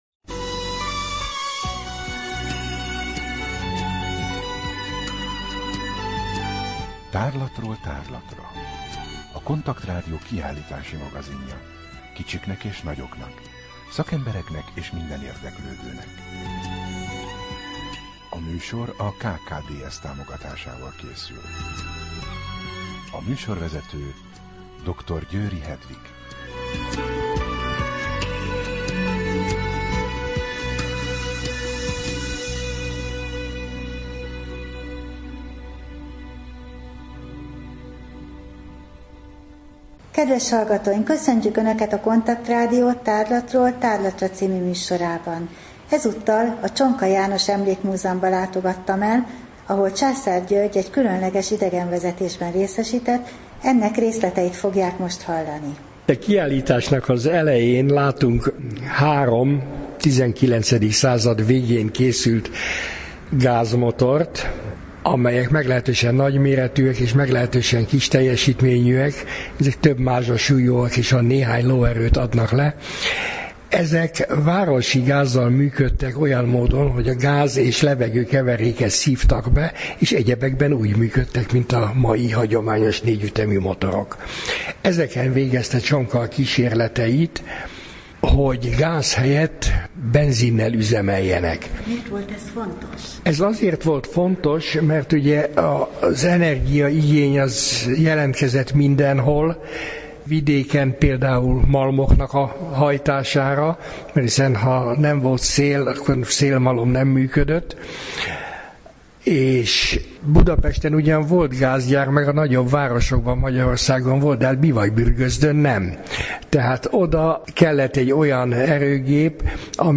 Rádió: Tárlatról tárlatra Adás dátuma: 2013, Szeptember 30 Tárlatról tárlatra / KONTAKT Rádió (87,6 MHz) 2013. szeptember 30. A műsor felépítése: I. Kaleidoszkóp / kiállítási hírek II. Bemutatjuk / Csonka János Emlékmúzeum, Budapest A műsor vendége